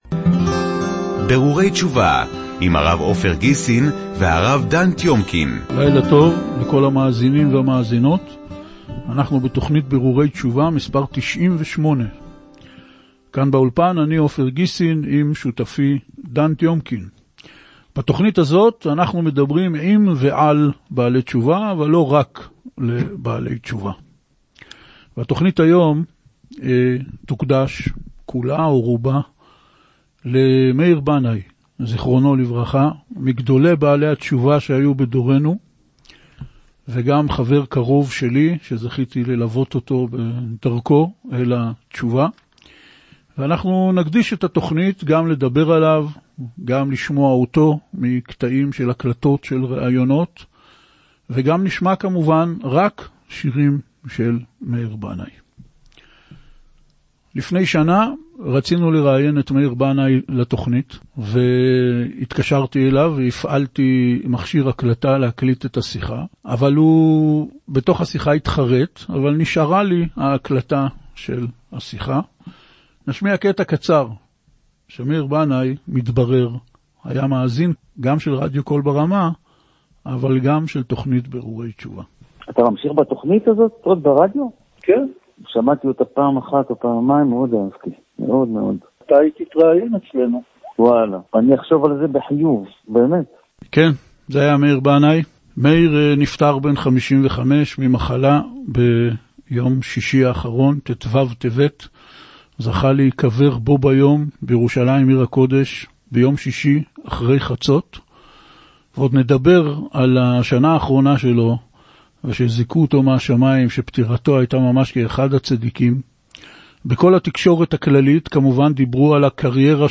תכנית רדיו ששודרה כמה ימים לאחר פטירתו.
קטעים מכתבות שנכתבו עליו ועל חייו ופטירתו, הקלטות נדירות שלו מראיונות ושיחות ושירים שיצר.
בתכנית קראנו קטעים מכתבות שנכתבו עליו ועל חייו ופטירתו.